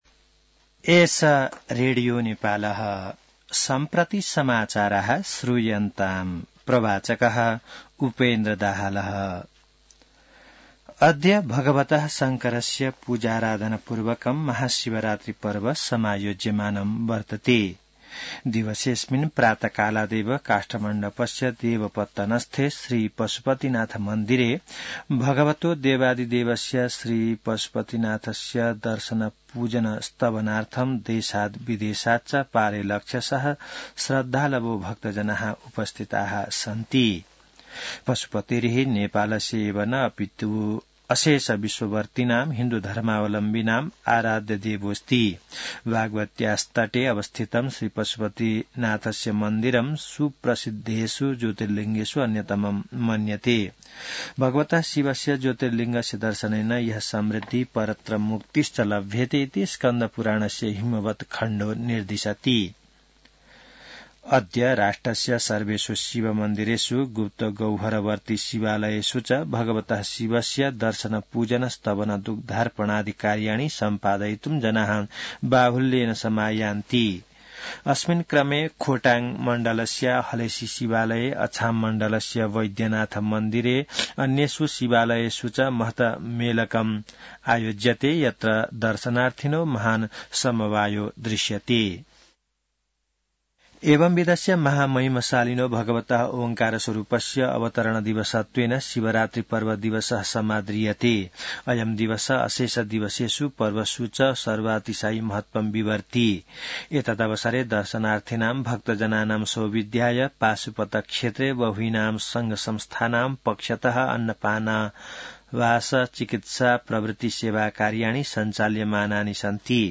संस्कृत समाचार : १५ फागुन , २०८१